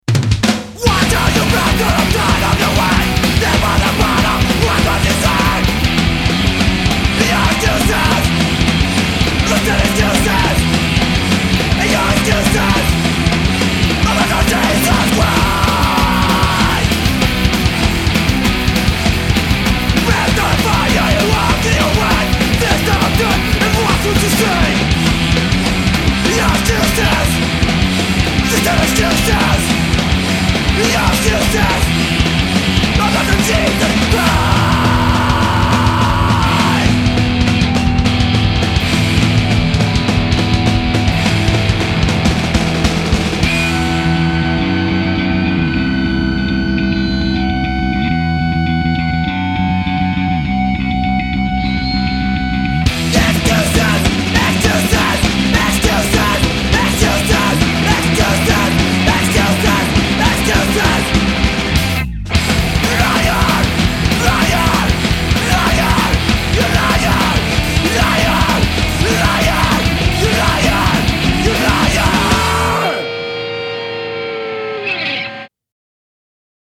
- 12 brand new raging tracks of hardcore punk